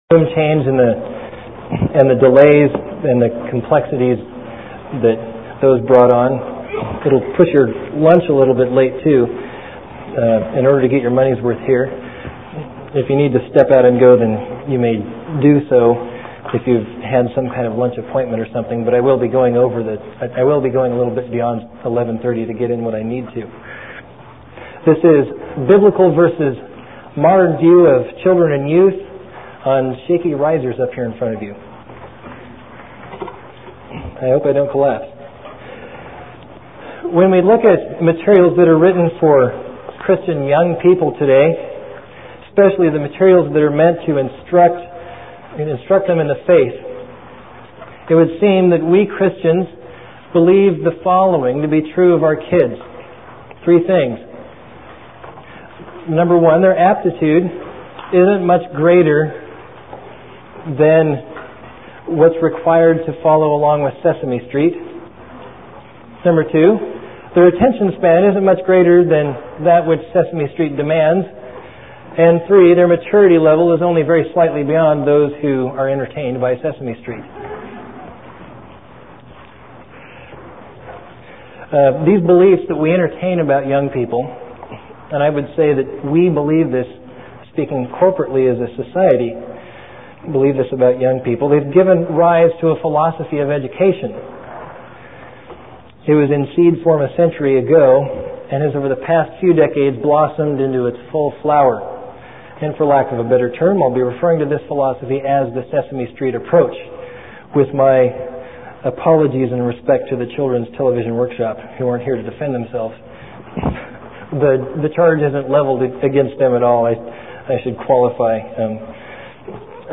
1995 Workshop Talk | 0:48:52 | All Grade Levels, History